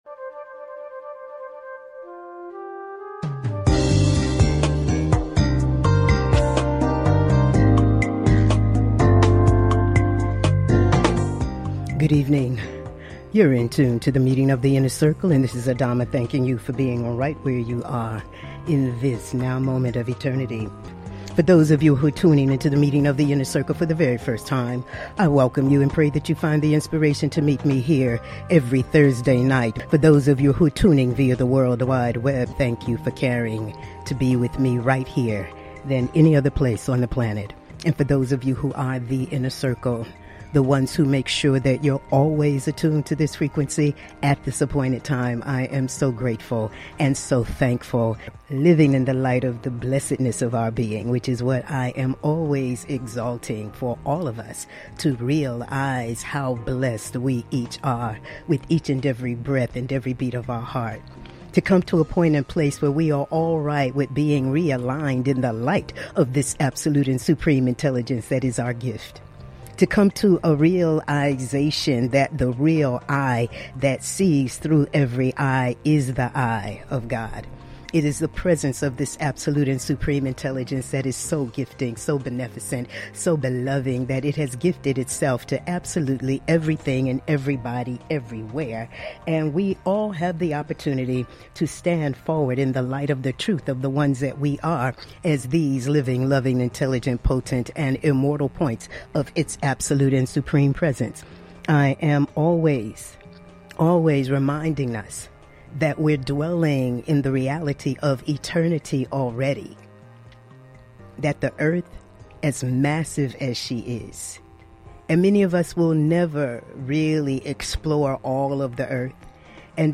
Monologues
Talk Show